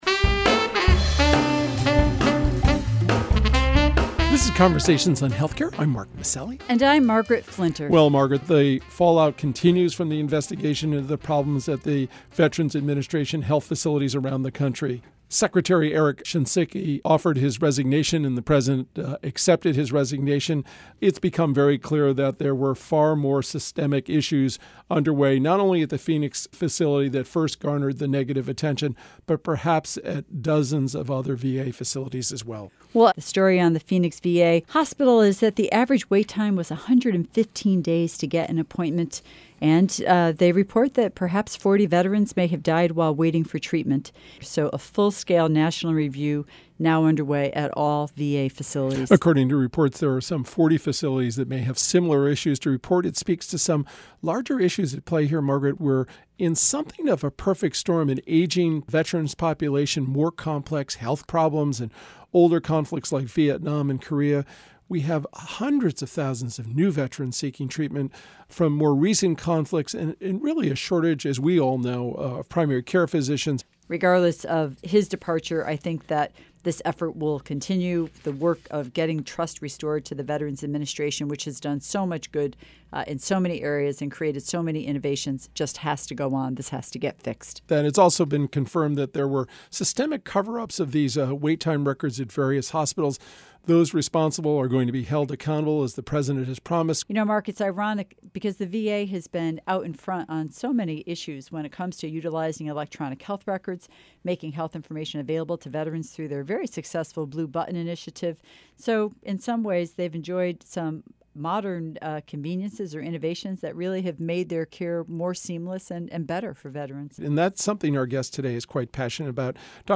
speak with Dr. Karen DeSalvo, National Coordinator for Health IT at the Office of the National Coordinator at HHS. Dr. DeSalvo discusses ONC's plans to advance Health IT beyond adoption to meaningful use and interoperability of health information technology, as well as health information exchange, to advance the triple aim of better care, better outcomes and reduced costs in health care.